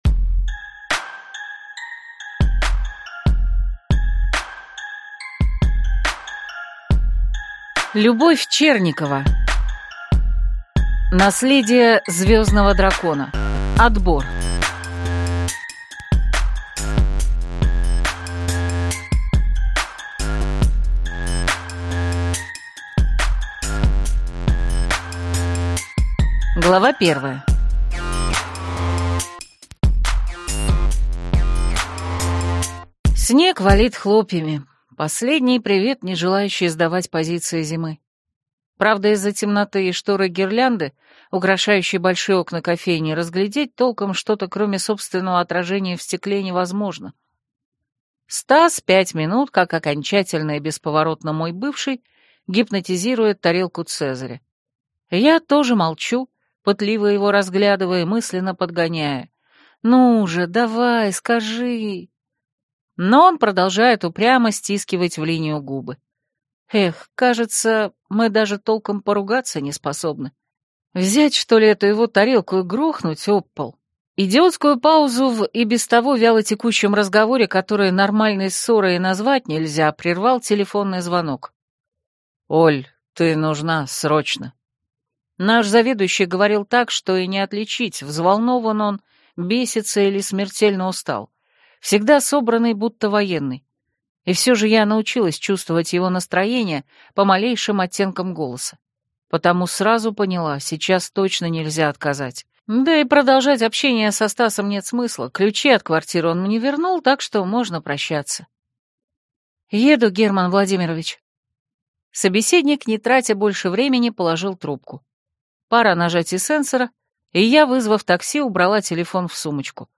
Аудиокнига Наследие звездного дракона. Отбор | Библиотека аудиокниг